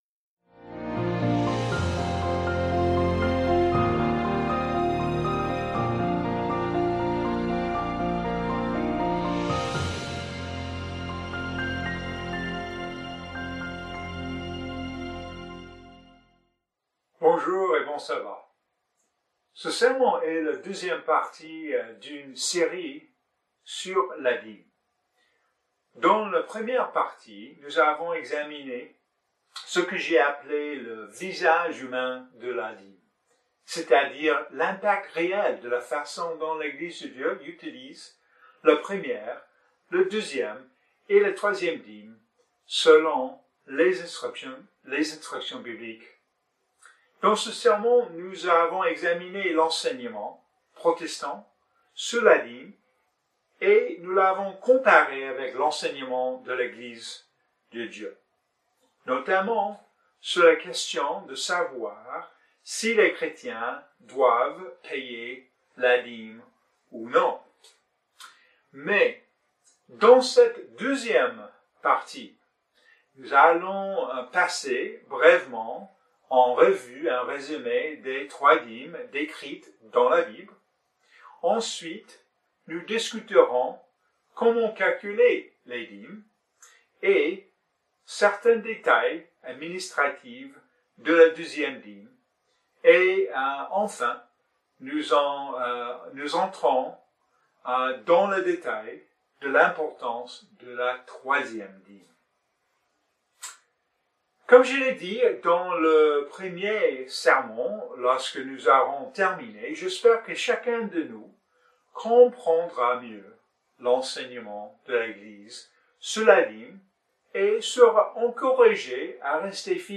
C‘est le deuxième sermon d‘une série de deux parties sur la dîme.